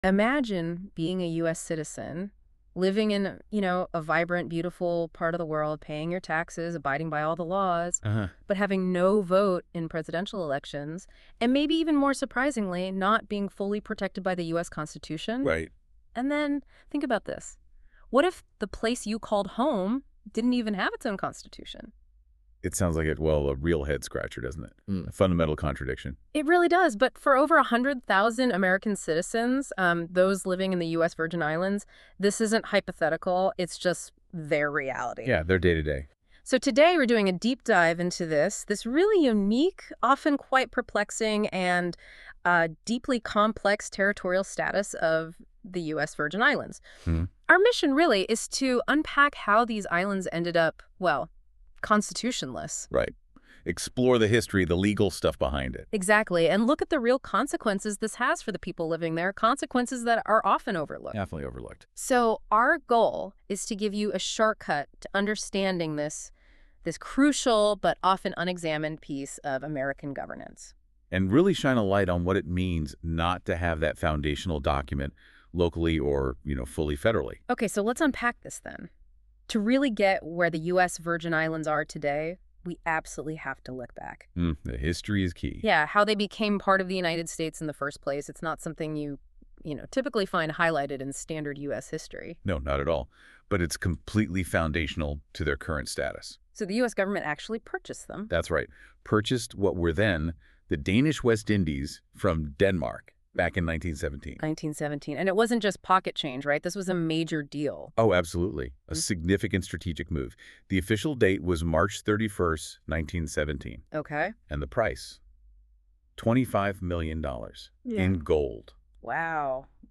This episode was generated using Google NotebookLM and reviewed for quality and accuracy by a Pasquines editor.